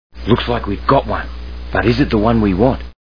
Crocodile Hunter TV Show Sound Bites